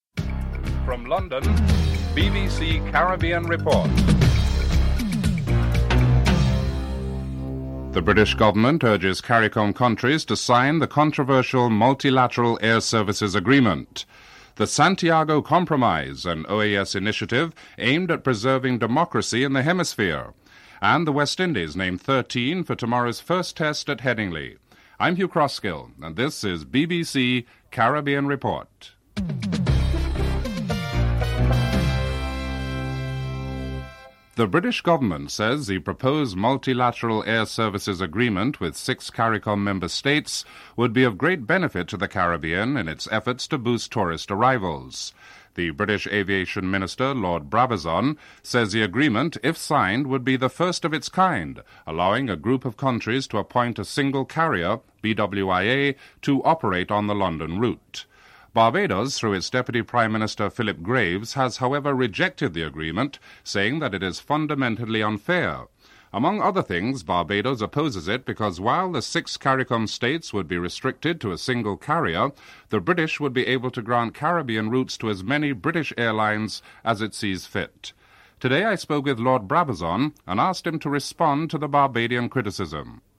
1. Headlines (00:00-00:33)
Sahadeo Basdeo, Trinidad and Tobago Foreign Minister, in Chile comments on if the agreement will cover both external and internal threats, and the chances of Cuba rejoining the association (05:23-09:35)